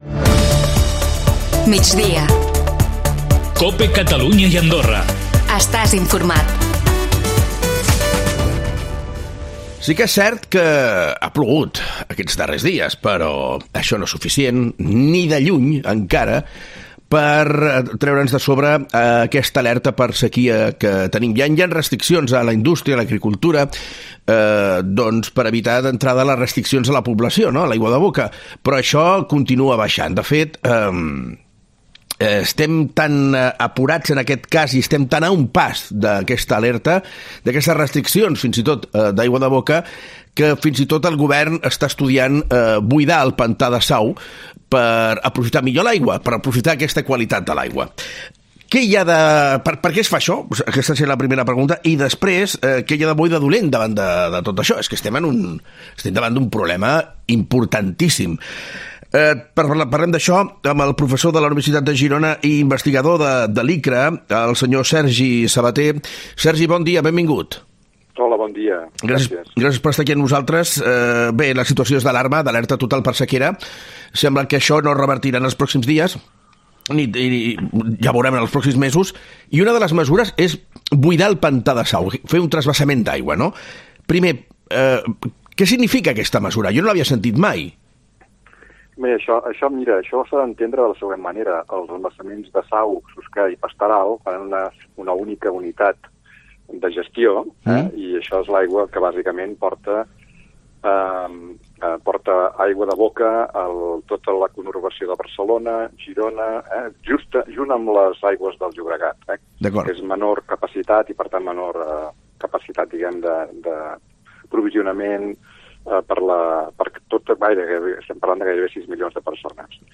(ESCOLTA L'ENTREVISTA AQUÍ) ctv-xqe-d87739d7-b35f-4bac-a594-2f4068ff4e44 Les restes de Sant Romà de Sau al descobert (ACN).